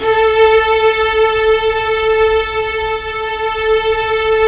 Index of /90_sSampleCDs/Roland LCDP13 String Sections/STR_Violins I/STR_Vls1 Sym wh%